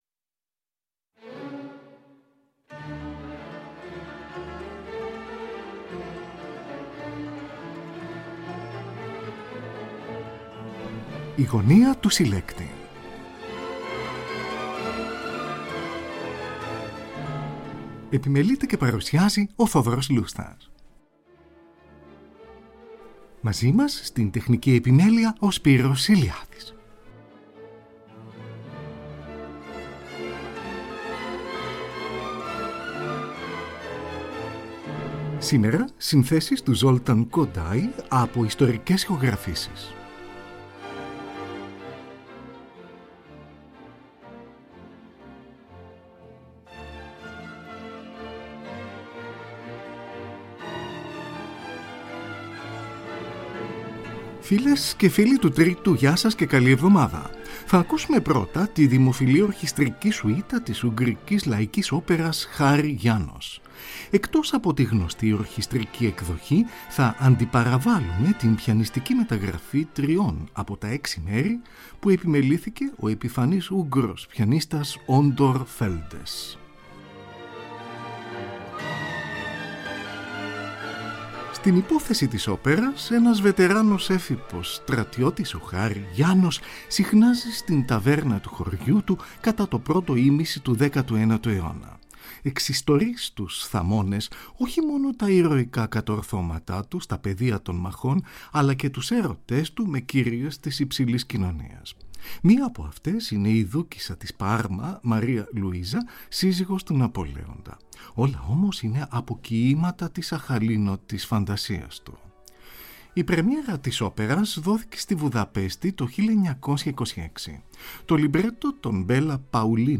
Oρχηστρική σουίτα